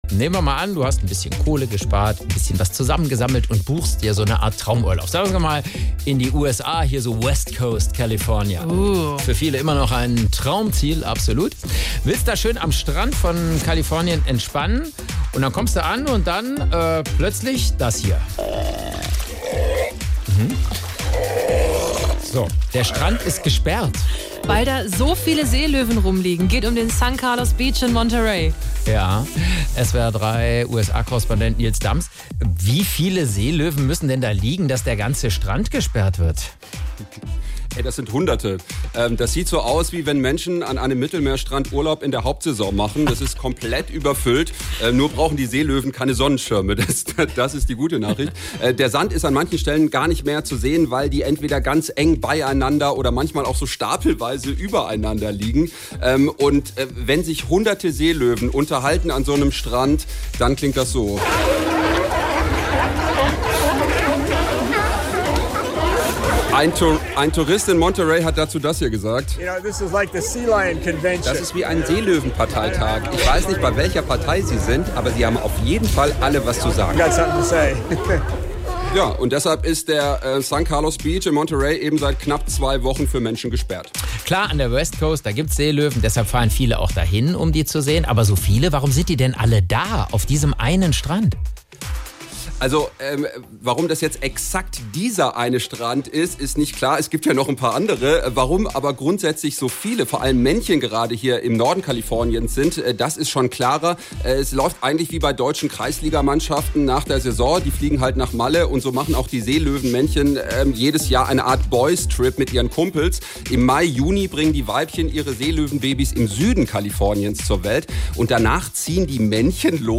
SWR3-Reporter
Nachrichten „Das sieht so aus, wie wenn Menschen am Mittelmeerstrand Urlaub in der Hauptsaison machen“